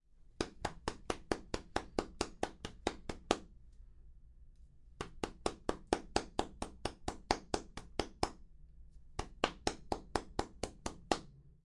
打脸声音包
Tag: 耳光